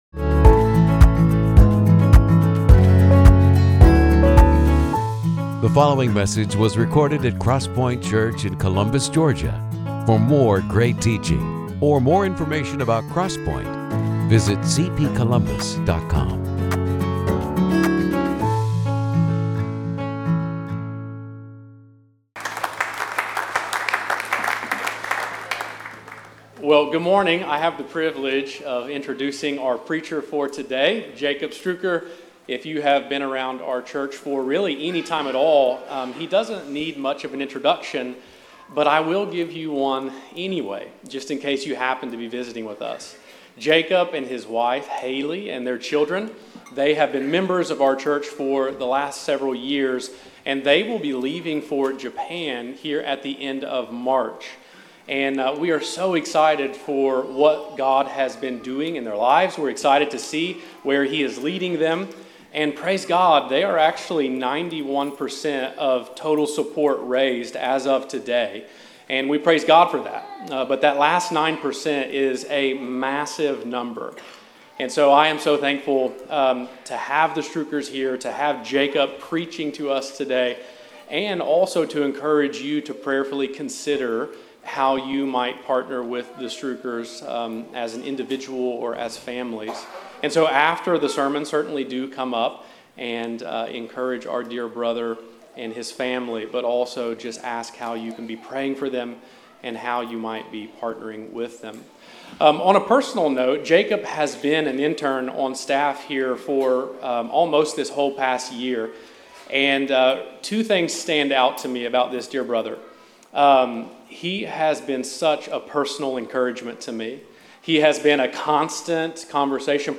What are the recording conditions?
The sermons of CrossPointe Church in Columbus, Ga.